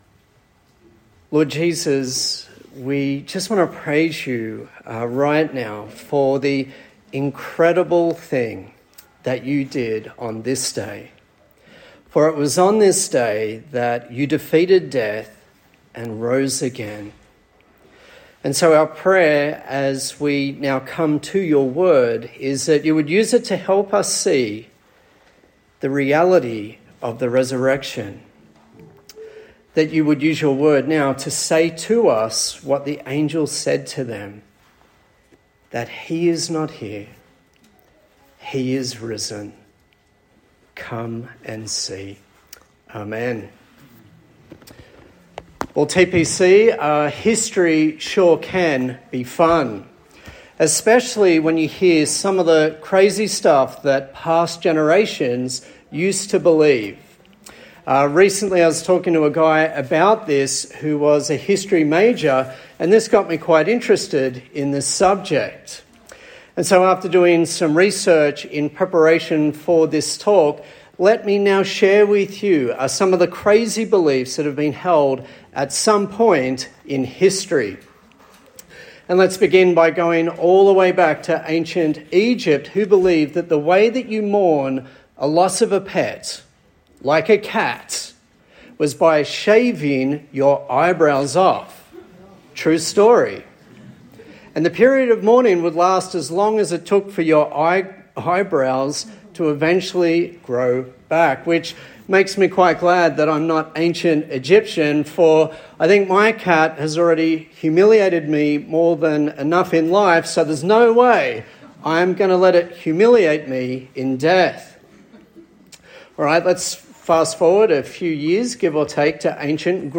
Passage: Matthew 27:45-28:15 Service Type: Easter Sunday